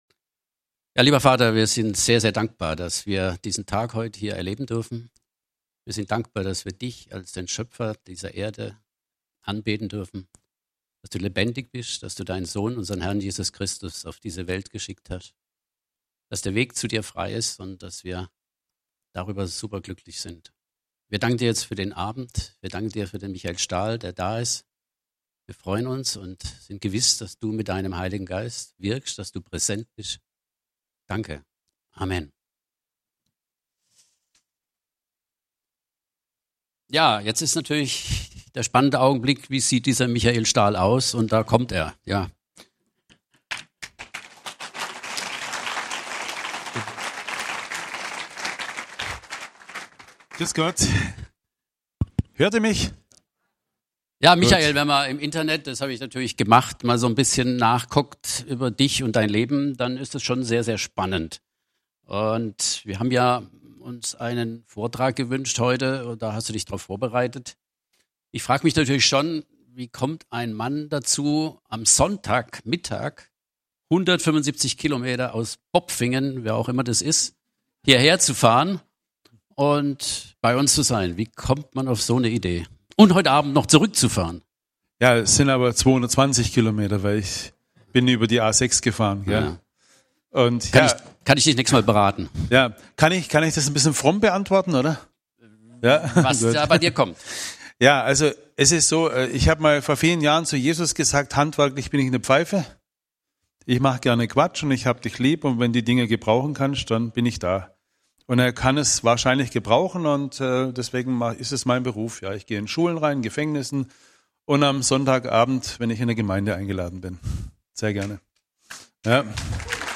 Ich vergesse, was dahinten ist - Christus ist mein Leben - Bibelstunde